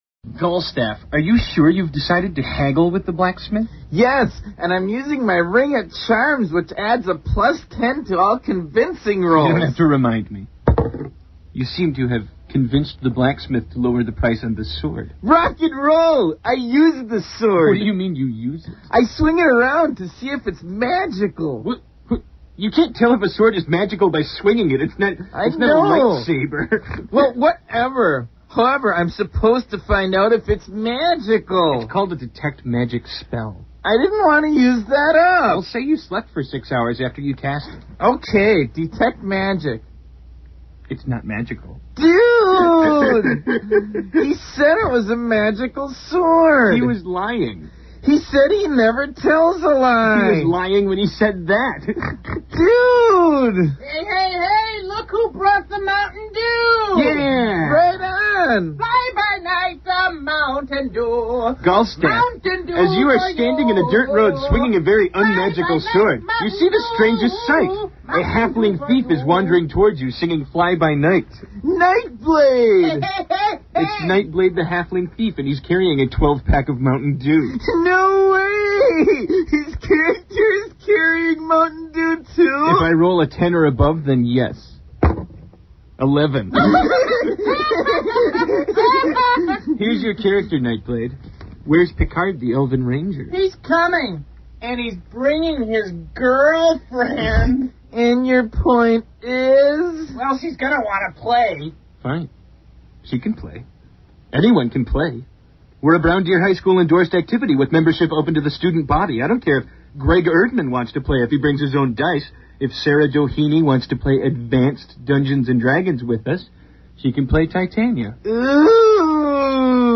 Many D&D players know – and gleefully quote – the Dead Alewives comedy sketch about the game (“I’m attacking the darkness!”).